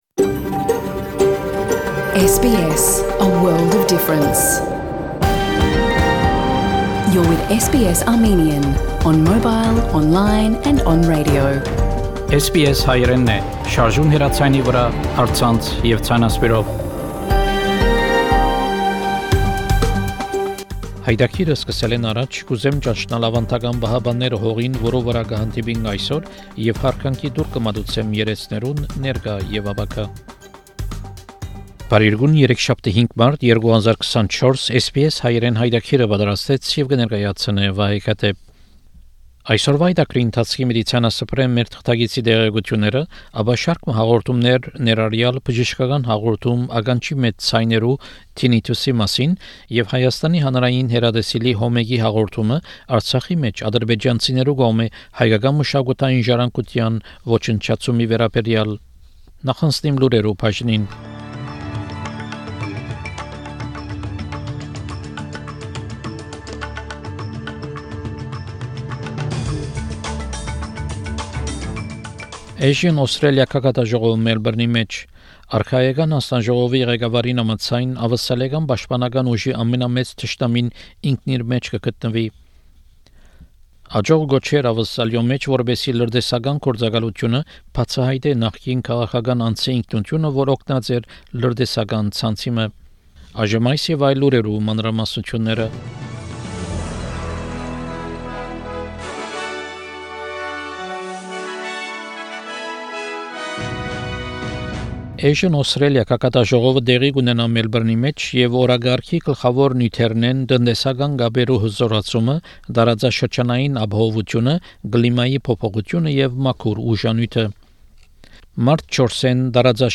SBS Armenian news bulletin – 5 March 2024
SBS Armenian news bulletin from 5 March program.